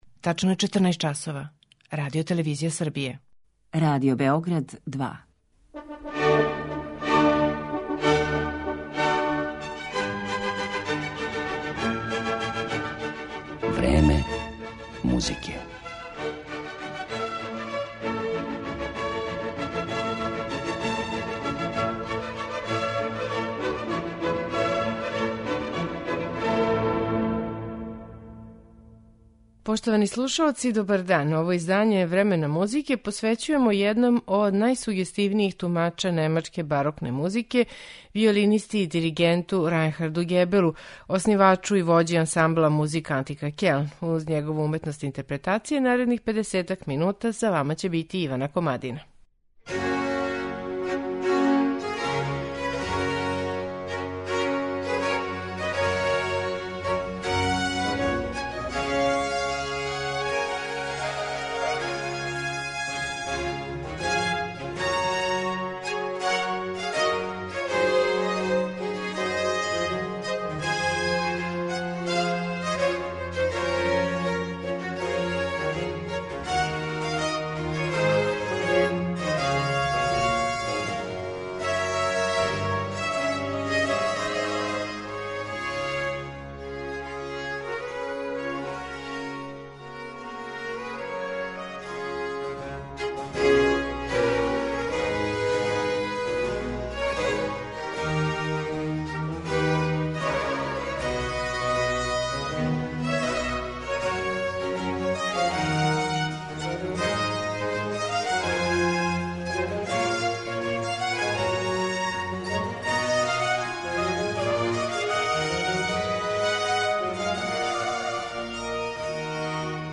брза темпа, избегавање вибрата, јаки динамички акценти.